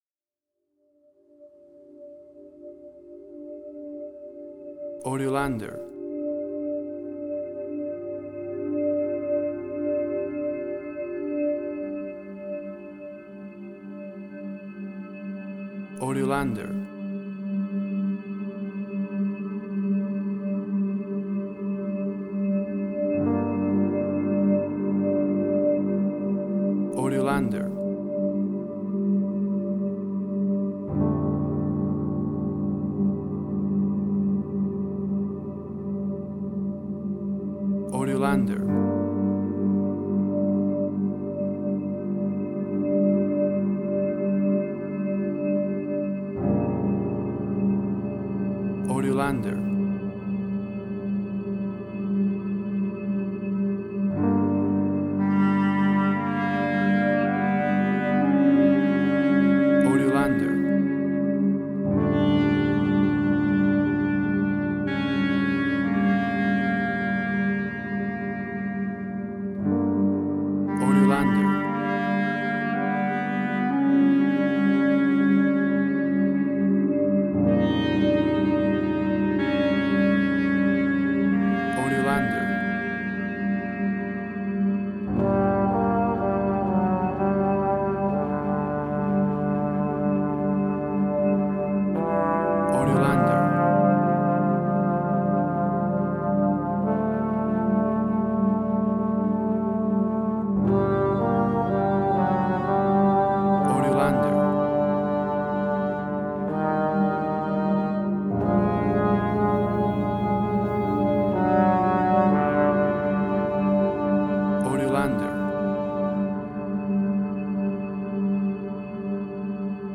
Modern Film Noir.